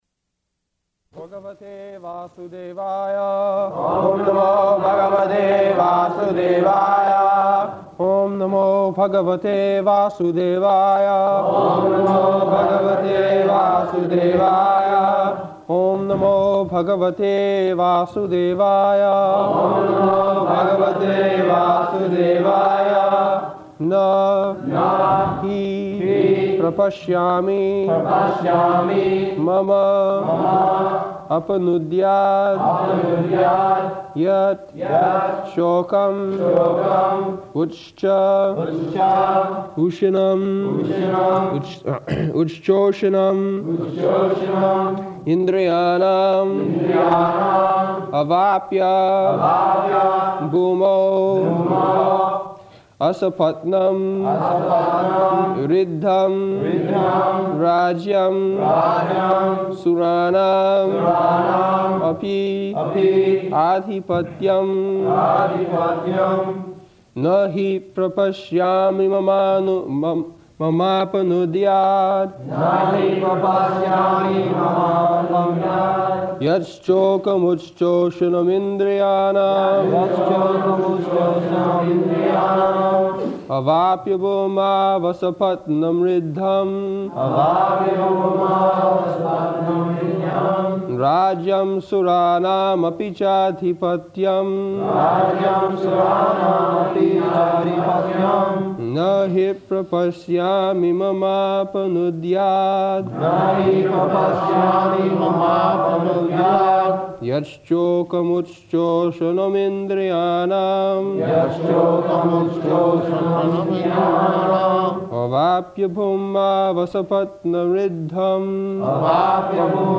August 8th 1973 Location: London Audio file
[Prabhupāda and devotees repeat]